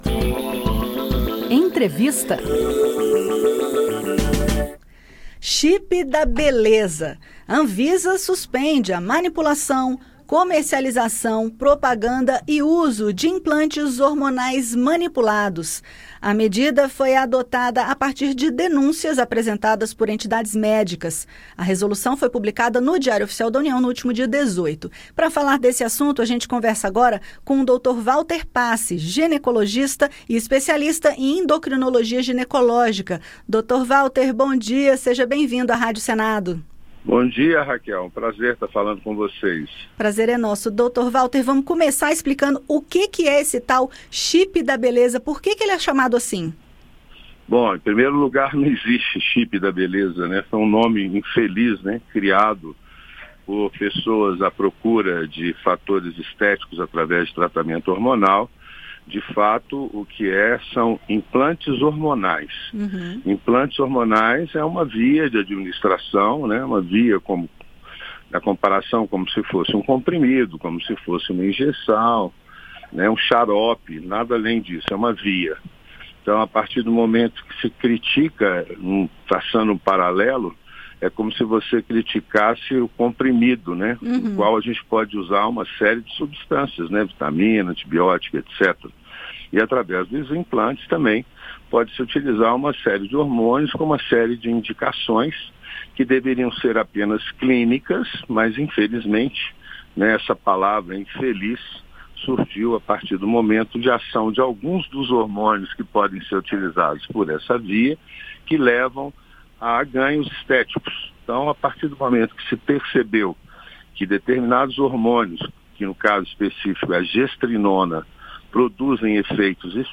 Ginecologista defende regulamentação de implantes hormonais e critica a comparação do procedimento a um "chip da beleza"